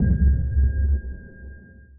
sonarTailSuitMediumShuttle1.ogg